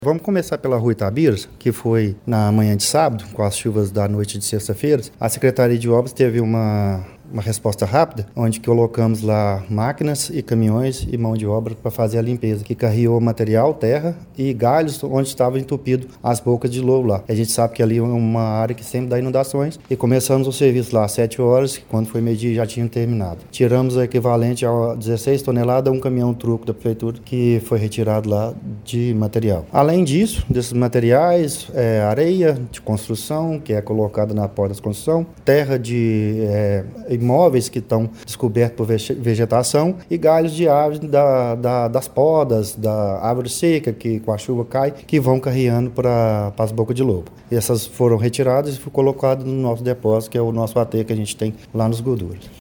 Durante coletiva de imprensa realizada nesta segunda-feira, 5, o secretário municipal de Obras e Infraestrutura, André Lara Amaral, apresentou um panorama das medidas adotadas pelo Município para enfrentar os danos provocados pelas fortes chuvas registradas no fim de semana passado.